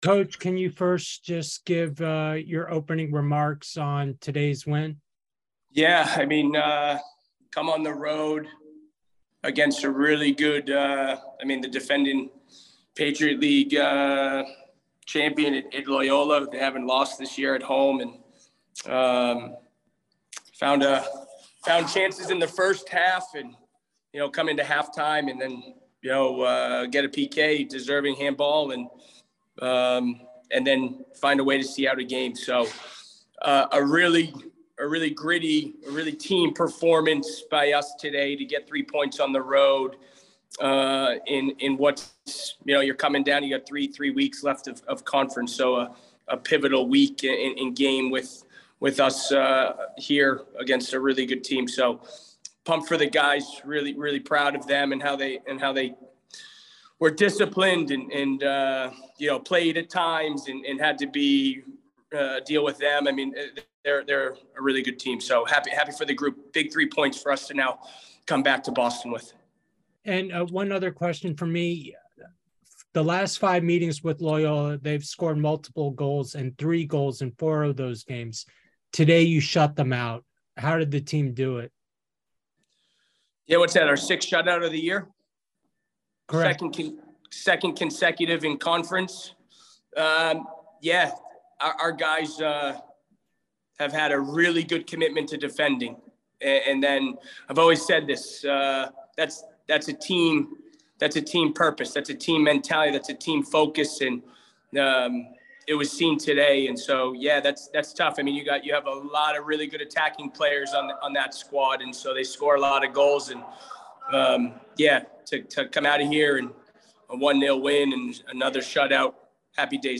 Men's Soccer / Loyola Md. Postgame Interview (10-15-22) - Boston University Athletics